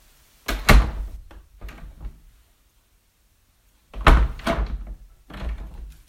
声音效果 " 塑料门关闭
描述：塑料大门音效。